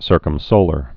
(sûrkəm-sōlər)